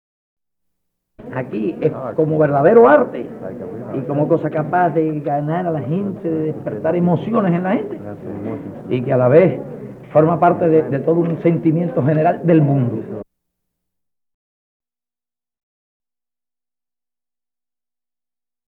01 - Voice of Fidel Castro.mp3